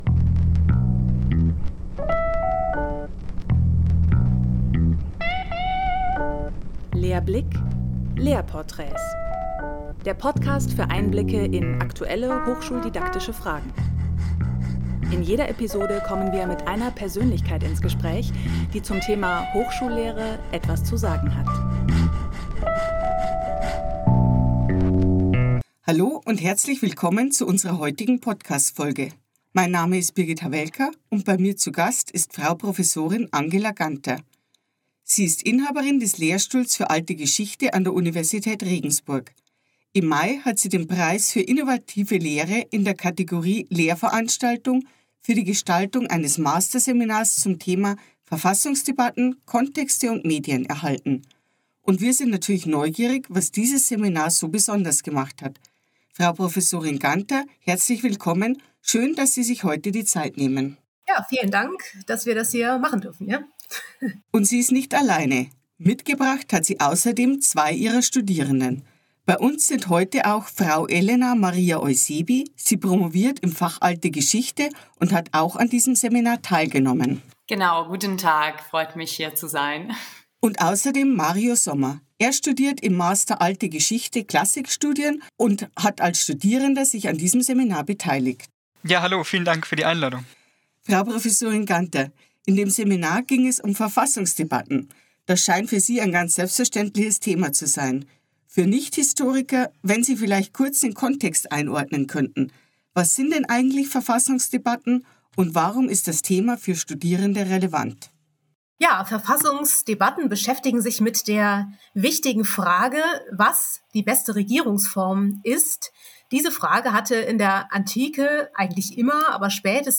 Podcast und Diskussion als innovative Lehrform im Fach Geschichte